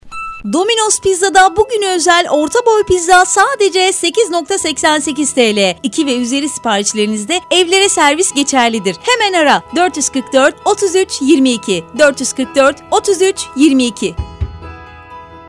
Sesli Mesaj